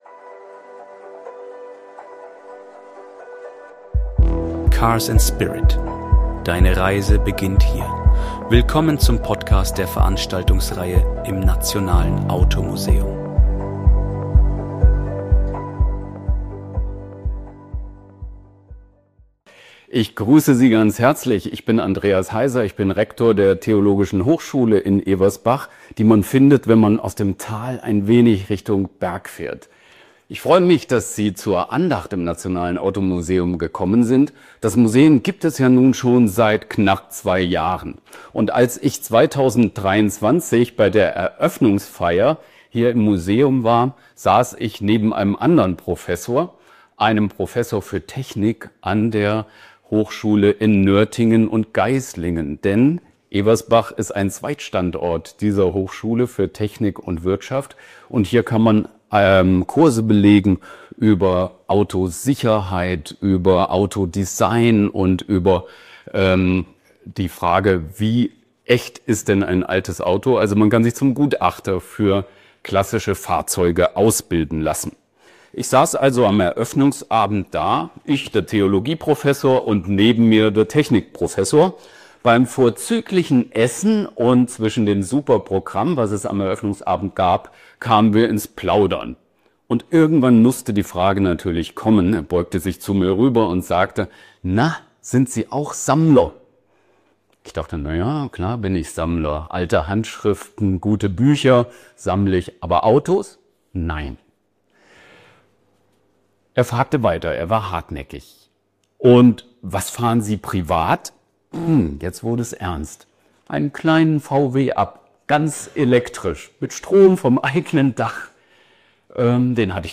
Andacht im Nationalen Automuseum in Ewersbach